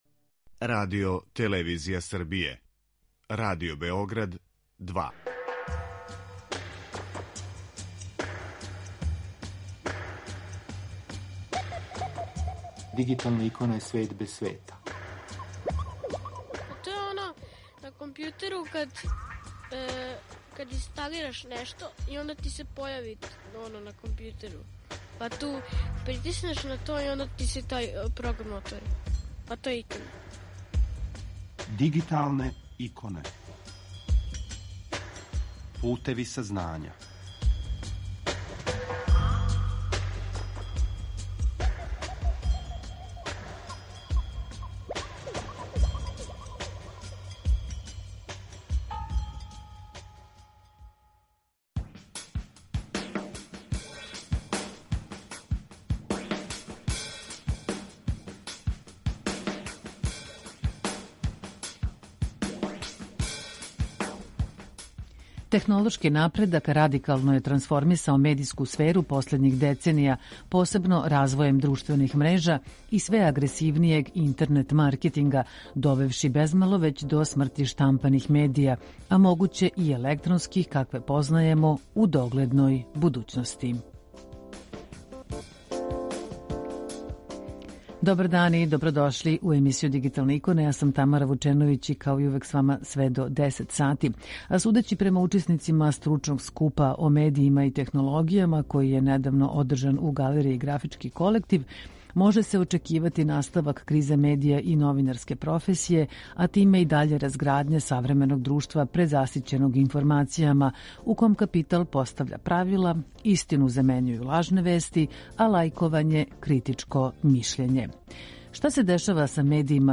Панел „Медији у технологији" одржан је у Галерији Графички колектив и био је посвећен трансформацији медија у дигиталној ери.
У данашњем издању слушамо одабране снимке са овог догађаја.